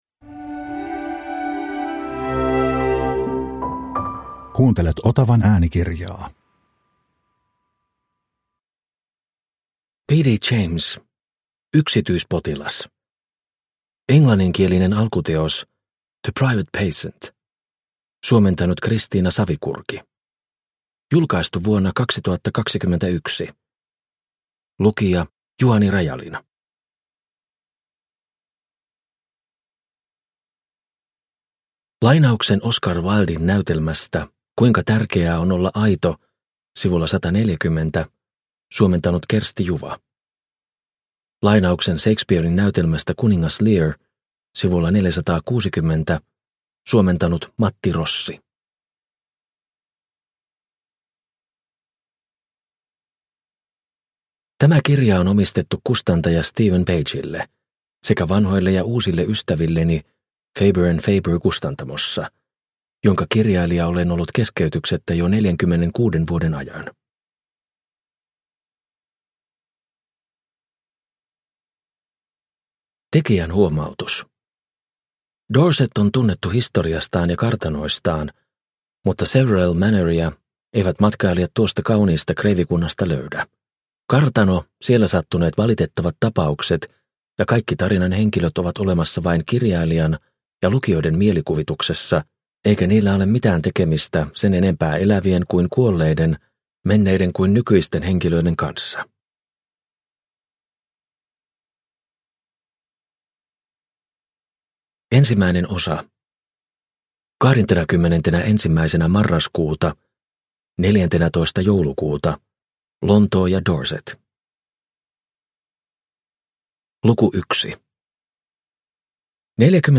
Yksityispotilas – Ljudbok – Laddas ner